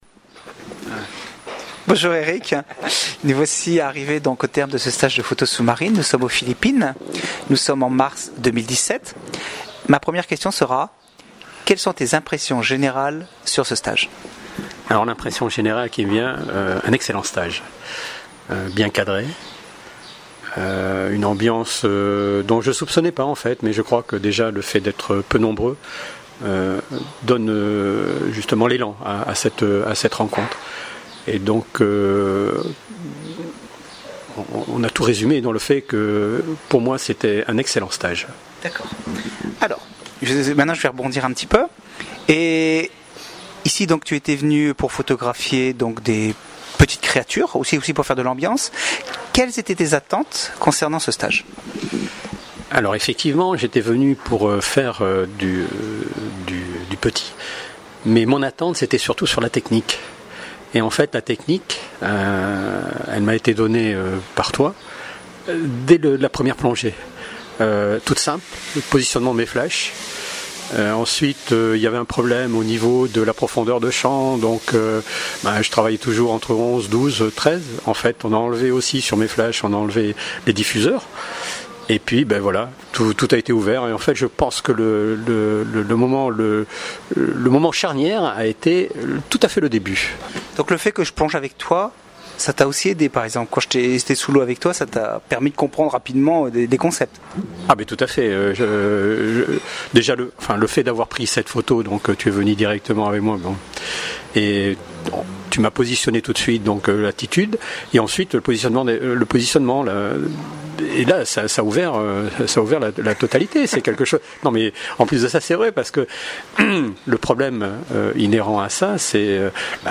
Témoignages écrits et oraux des participants